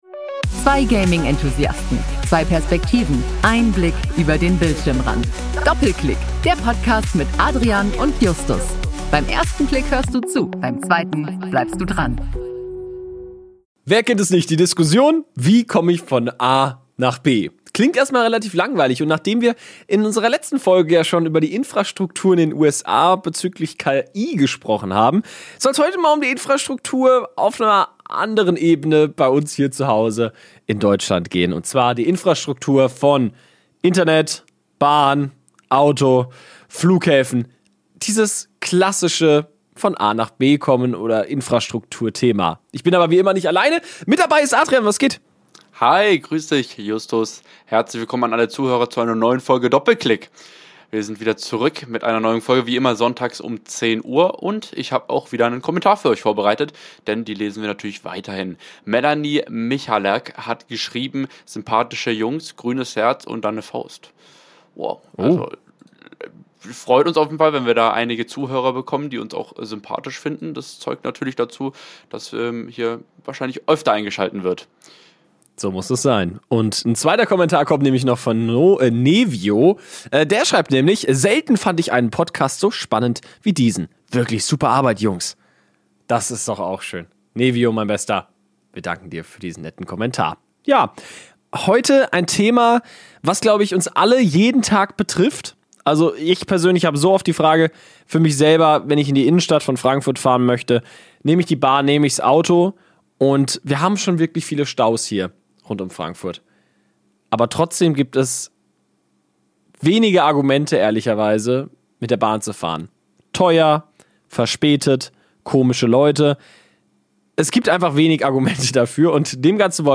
Die beiden diskutieren über verpasste Chancen, politische Versäumnisse und persönliche Erfahrungen mit Bahn-Chaos und lahmem Internet.